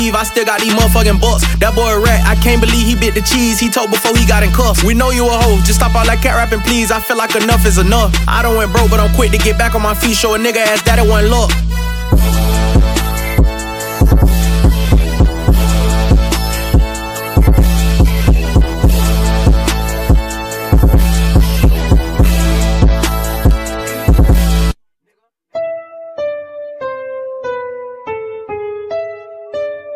Жанр: Хип-Хоп / Рэп